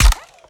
Added more sound effects.
GUNAuto_RPU1 Fire_06_SFRMS_SCIWPNS.wav